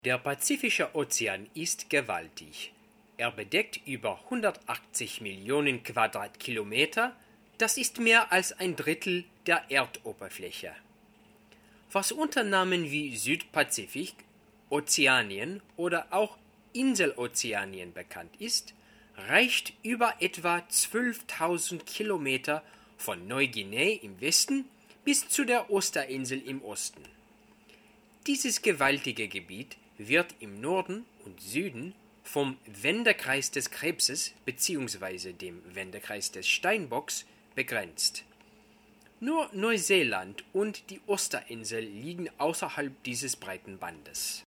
Gender Male
Voice Sample